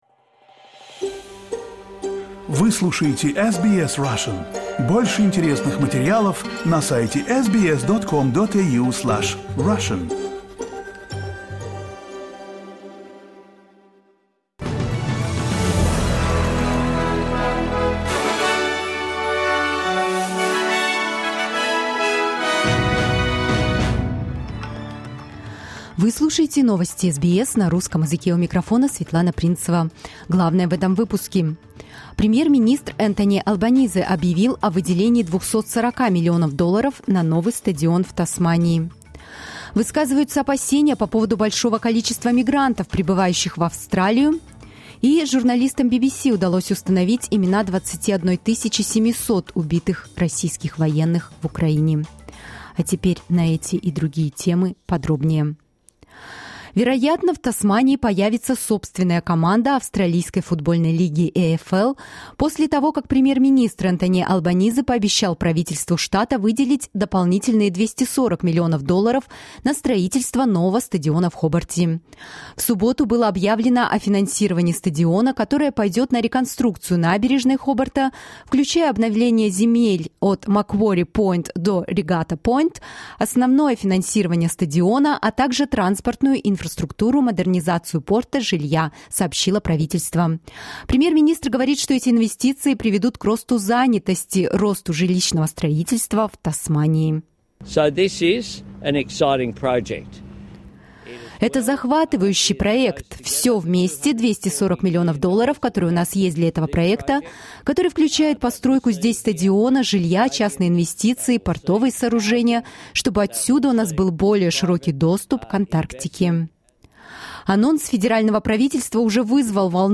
SBS news in Russian — 29.04.2023